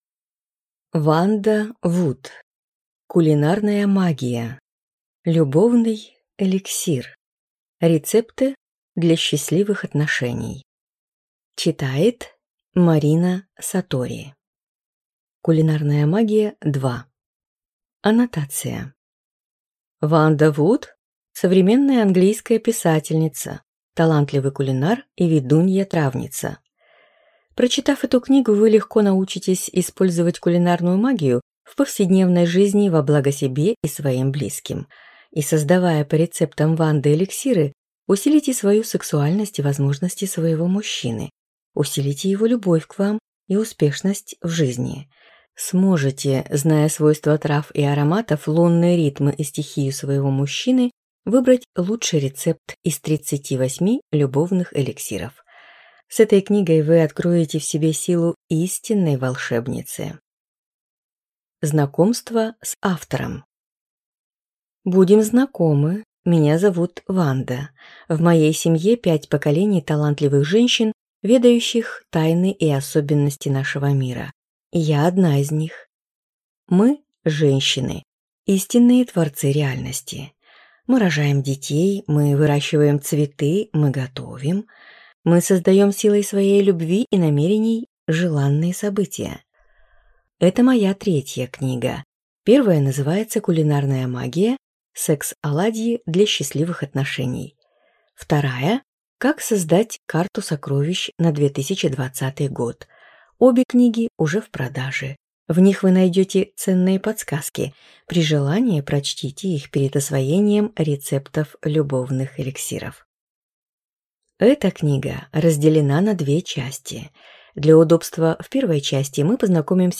Аудиокнига Кулинарная магия. Любовный эликсир. Рецепты для счастливых отношений | Библиотека аудиокниг